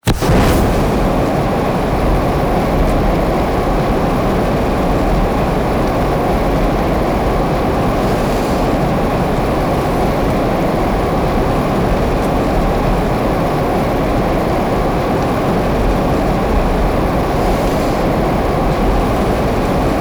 cookoff_mid_pressure.ogg